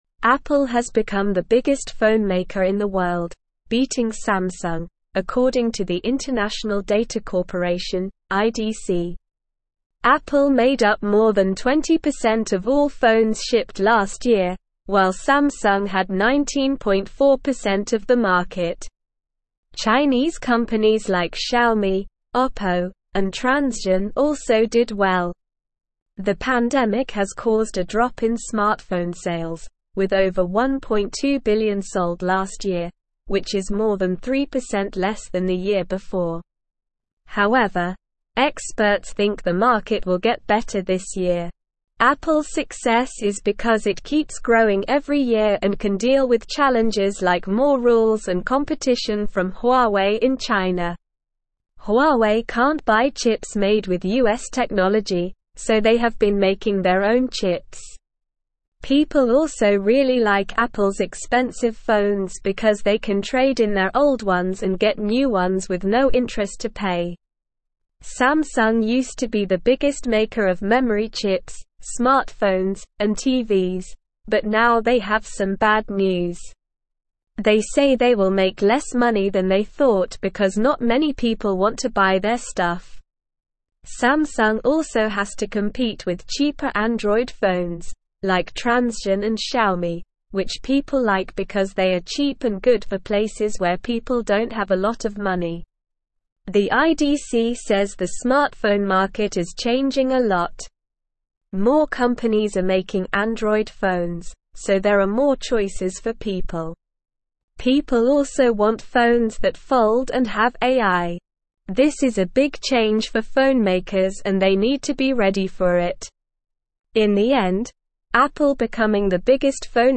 Slow
English-Newsroom-Upper-Intermediate-SLOW-Reading-Apple-Surpasses-Samsung-as-Worlds-Largest-Phonemaker.mp3